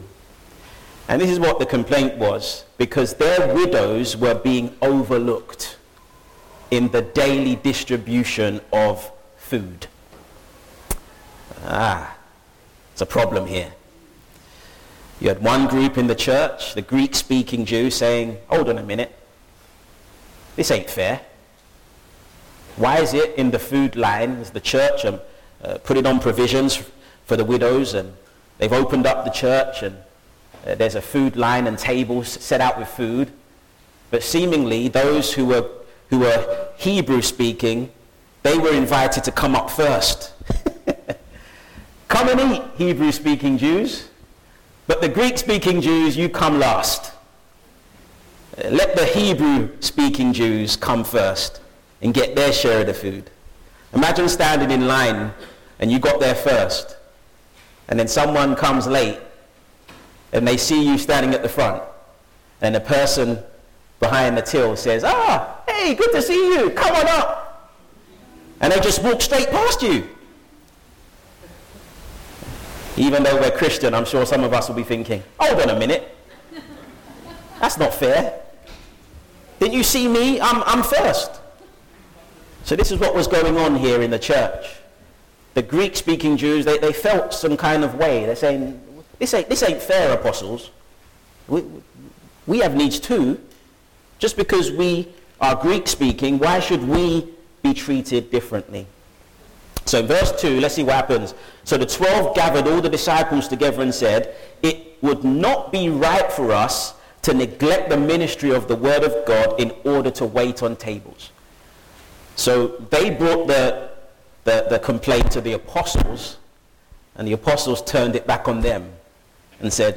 Join us this Sunday for our service including a presentation about the work of Compassion UK.
Service Audio